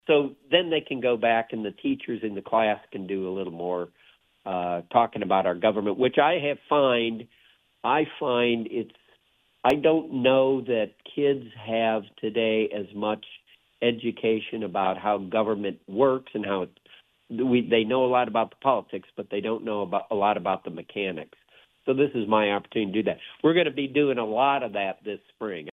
Many legislators do school visits, and Congressman Pence spoke on WMUN about the benefits…